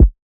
TS - Kick (6).wav